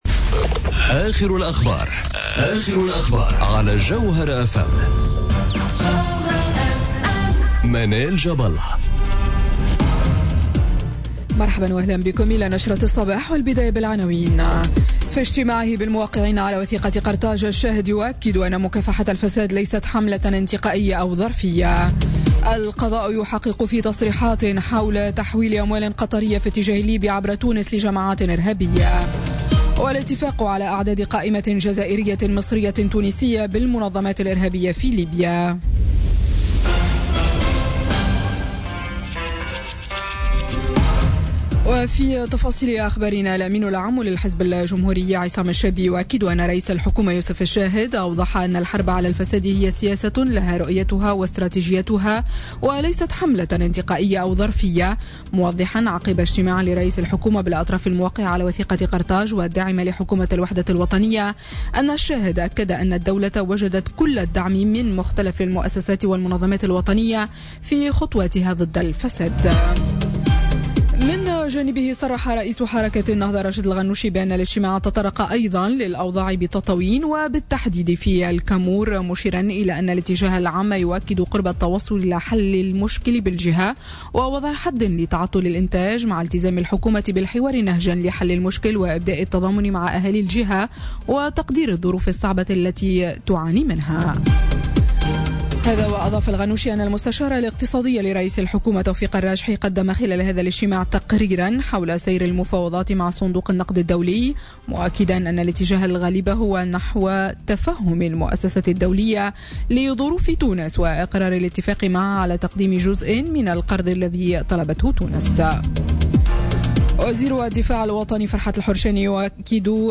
نشرة أخبار السابعة صباحا ليوم السبت 10 جوان 2017